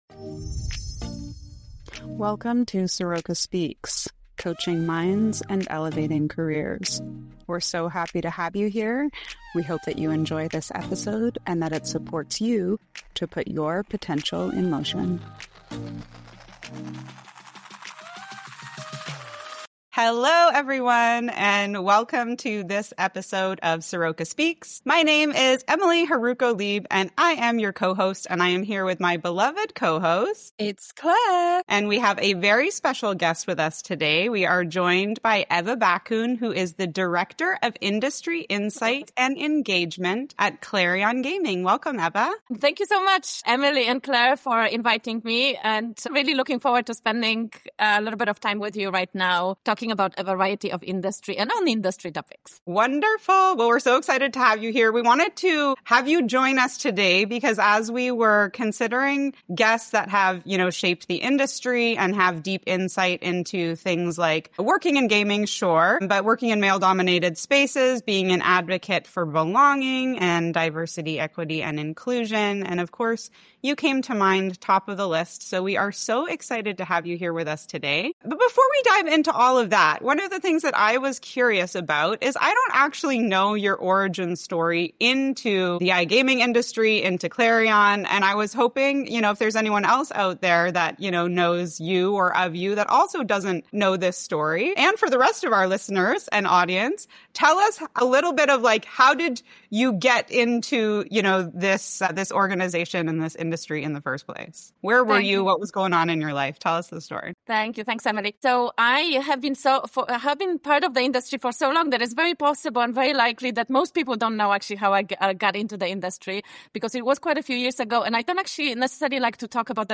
In this powerful conversation